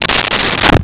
Desk Drawer Open 02
Desk Drawer Open 02.wav